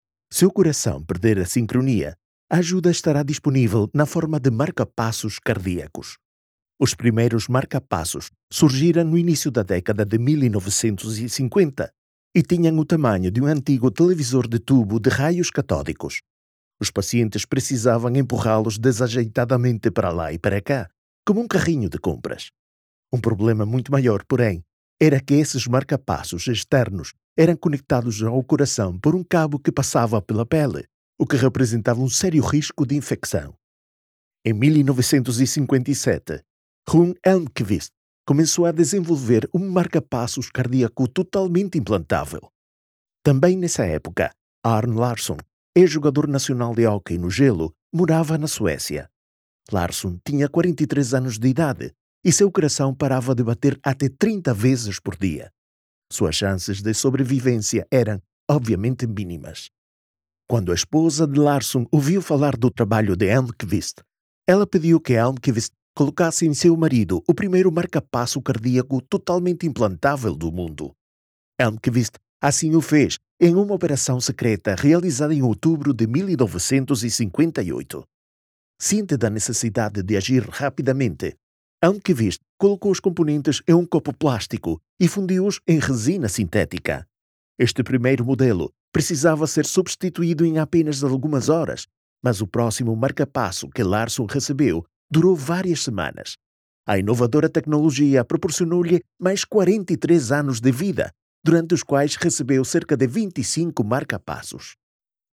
Commercial, Distinctive, Accessible, Versatile, Reliable
Audio guide
His voice is often described as fresh, young, calm, warm, and friendly — the “guy next door” — ideal for commercials, audiobooks, narration, promos, YouTube and educational content, e-learning, presentations, and podcasts.
As a full-time producer, studio owner, and musician, he ensures clean, echo-free, uncompressed audio, delivered in any format.